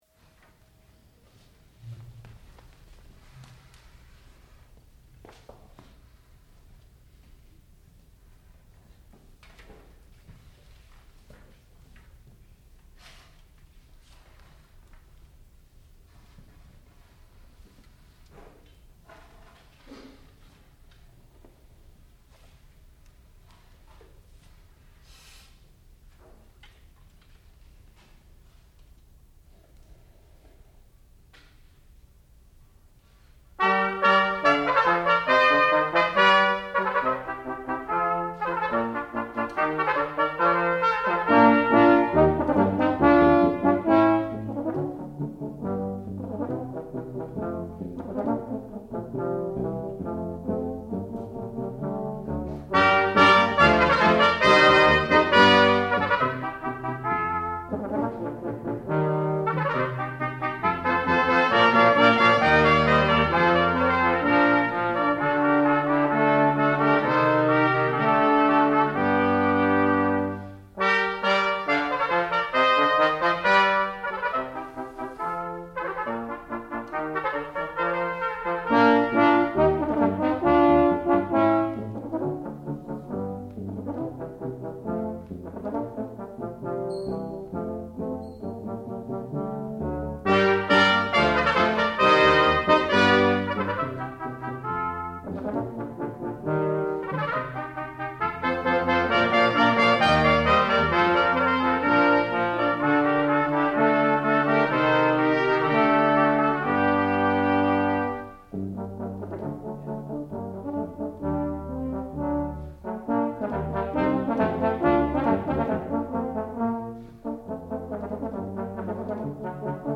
sound recording-musical
classical music
trumpet
trombone